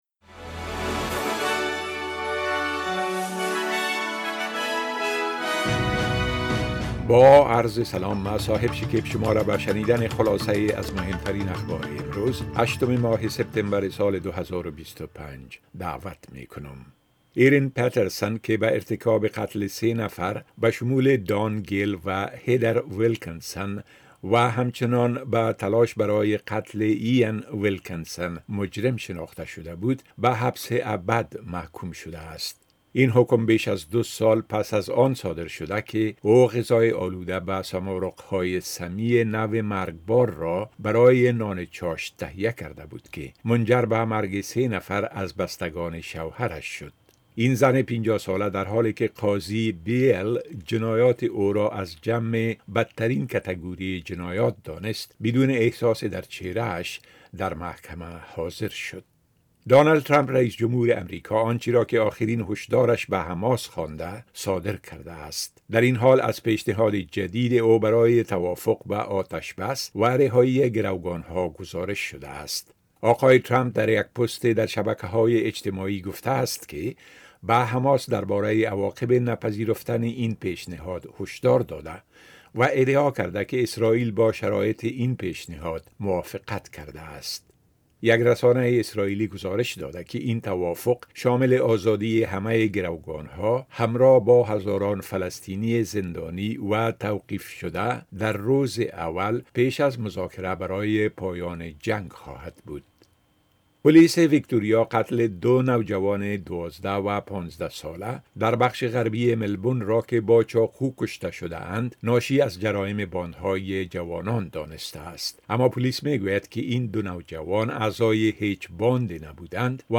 خلاصه مهمترين خبرهای روز از بخش درى راديوى اس‌بى‌اس